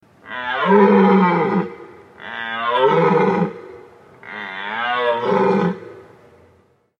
На этой странице собраны натуральные звуки оленей: от нежного фырканья до мощного рева в брачный период.
Голос обычного оленя